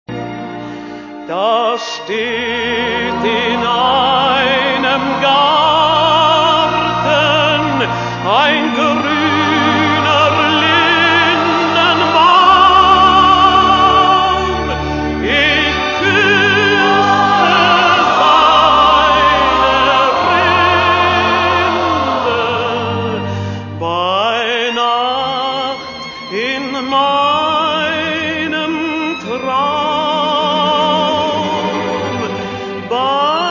Aufnahme mit 5kB/s, fs=16kHz Hörprobe